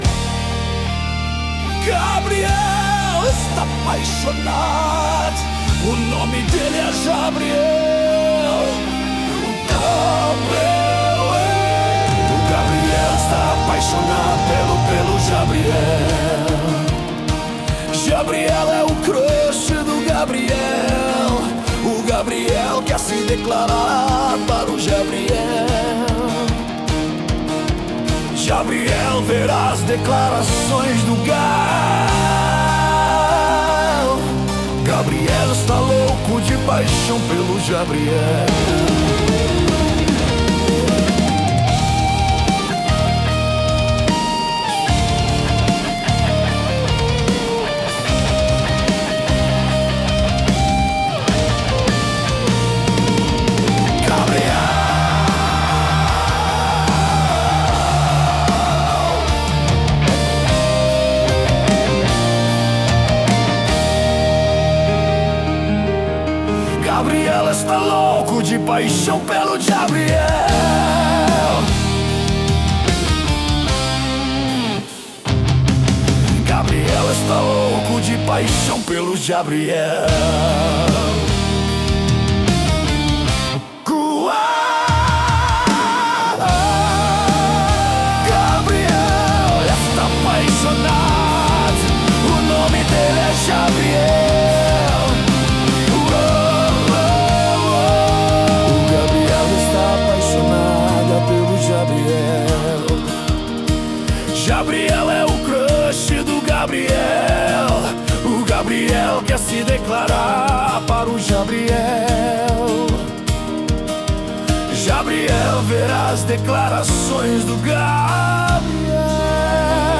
Versão Metal 1